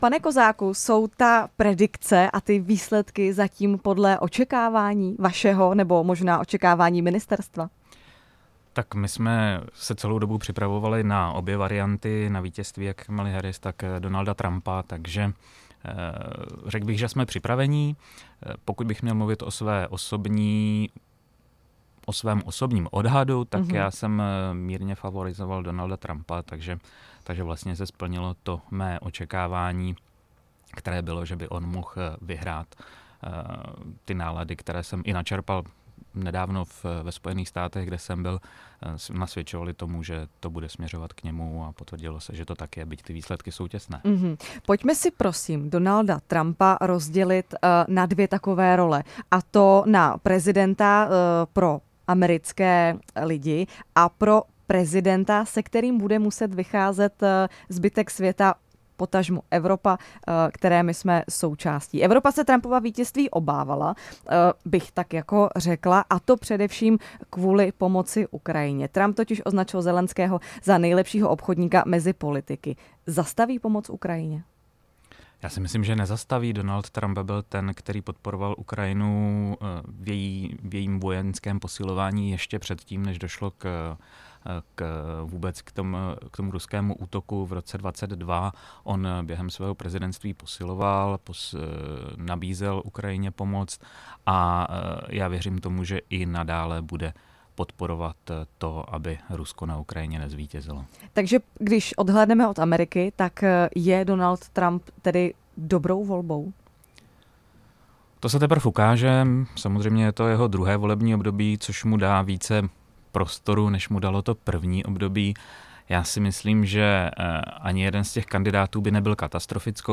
Ve studiu Radia Prostor komentoval aktuální volební situaci také náměstek ministra zahraničních věcí Jiří Kozák.